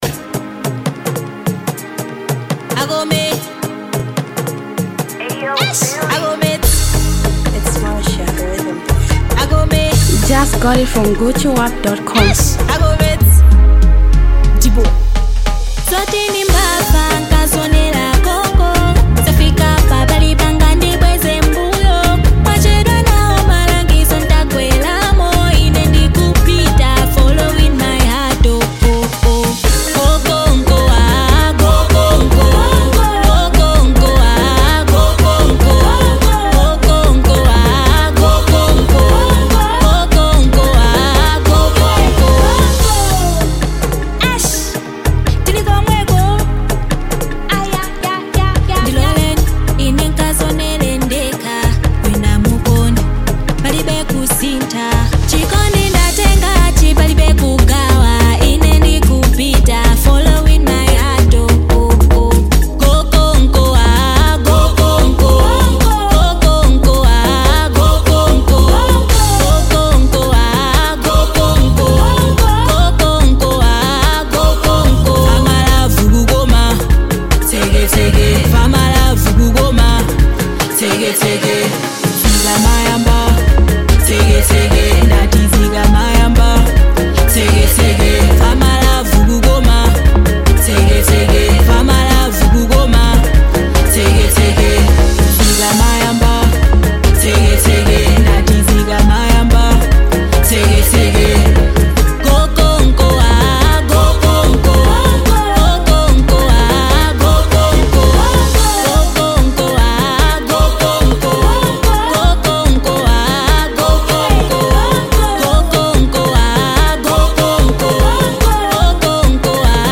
Malawian eminet singer, songwriter
buzzing street sound